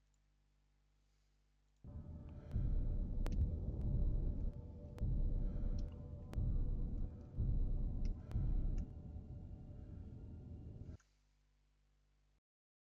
Before patch all work like charm but after patch when activate thrusters i hear noise which sound like your wire is physical damaged and headset get bad contact or something similar its like crack crack crack sound. and after patch i dont hear anymore collector shuup soound when its suck some items in it. computer and headset drivers are same before patch.
i have voicemeter potato to manage audio so its voice meter aux input normal stereo audio
best to hear pop pop sound is when using ship with ion thrusters
so dedicated server -> enable realistic audio = crack pop sound when thrusters active.